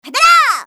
academic_f_voc_skill_dynamicvoltex_01.mp3